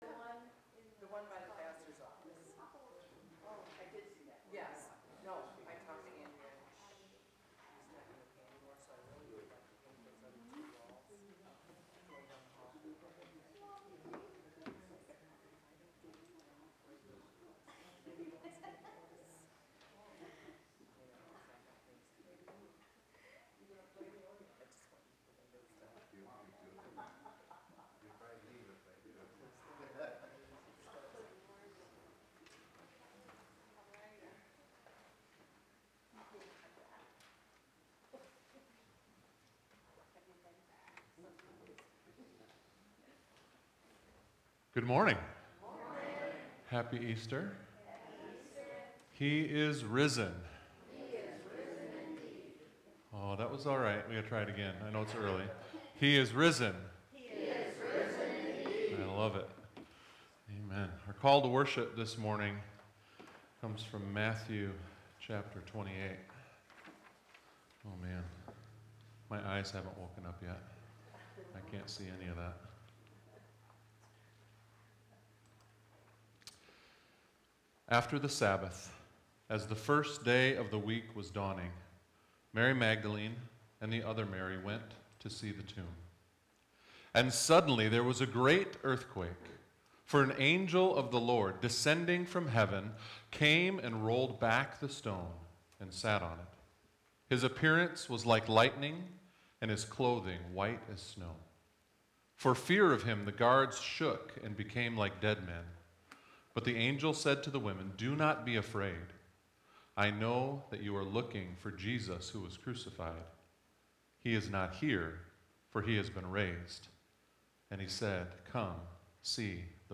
Easter Sunrise Service